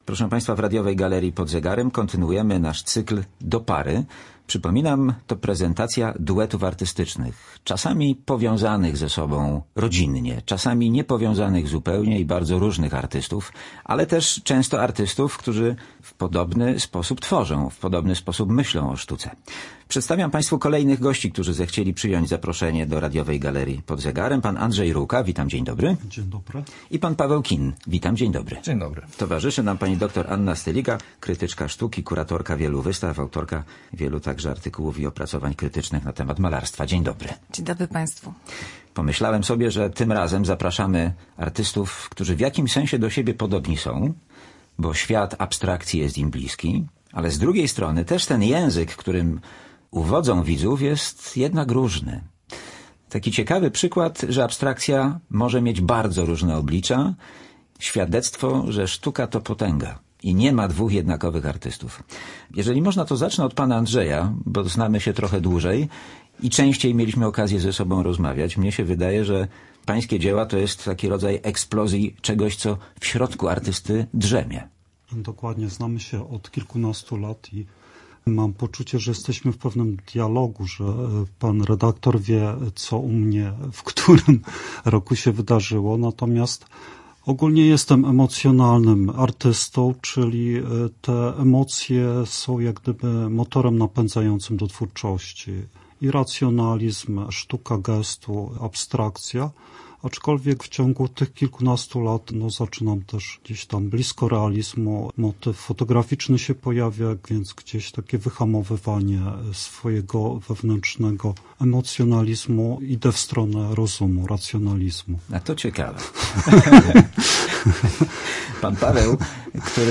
Z malarzami
krytyczką sztuki rozmawia